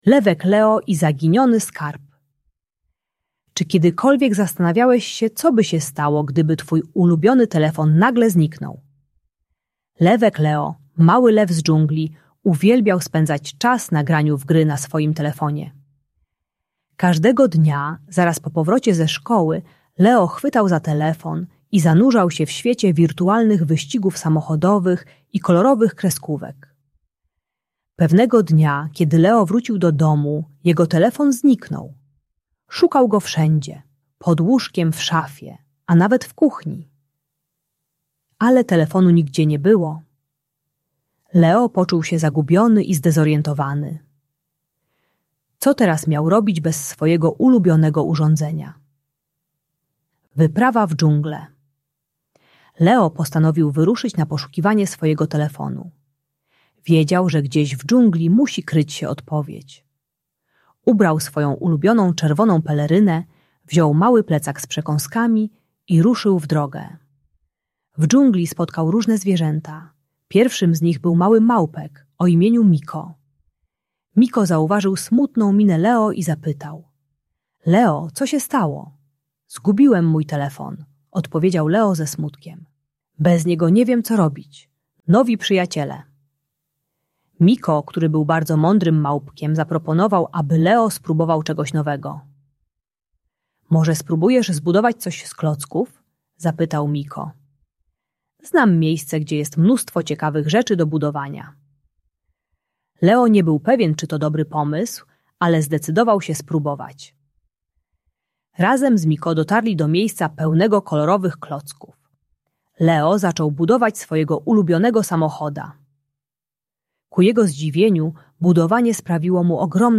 Audiobajka o uzależnieniu od telefonu uczy techniki "jeden dzień bez ekranu" i pokazuje, że zabawa z przyjaciółmi i kreatywne budowanie są równie ekscytujące jak gry na telefonie.